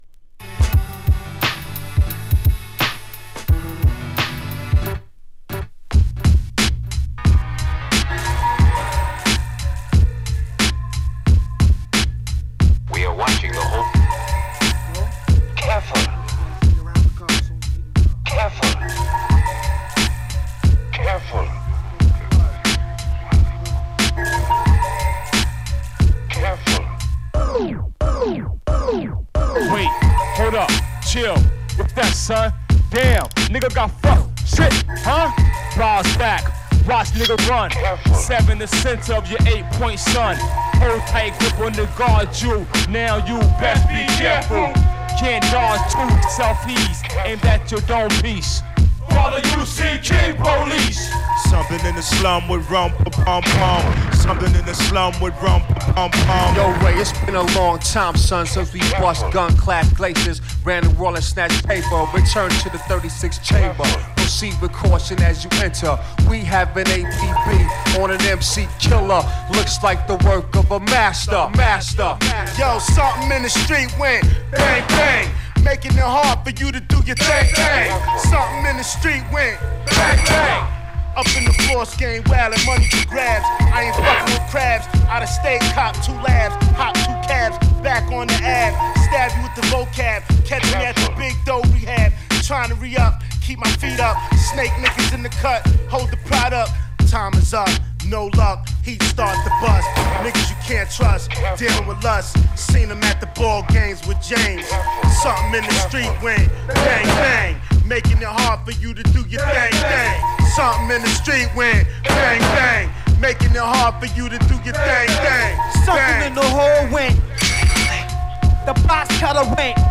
煙たい中毒性のあるトラックに刀の抜刀や鍔迫り合いなどの音をサンプリングした武闘派らしい仕上りになってます。